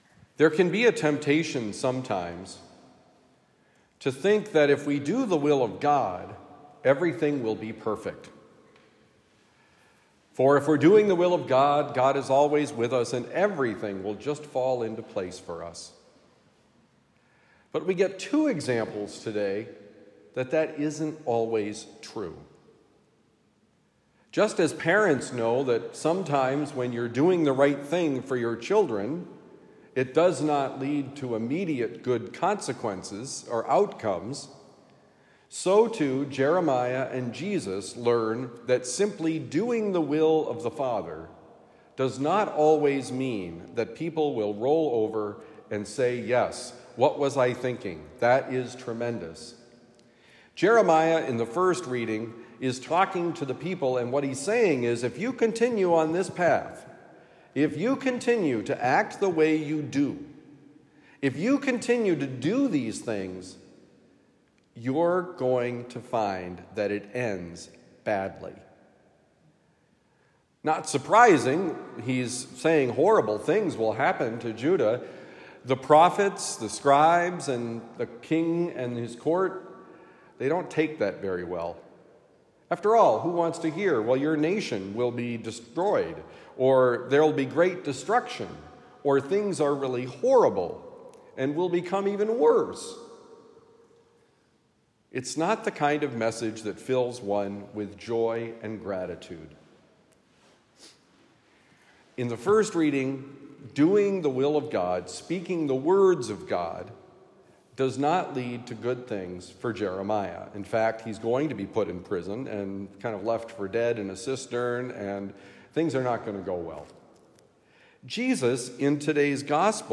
Following God can mean rejection: Homily for Friday, August 2, 2024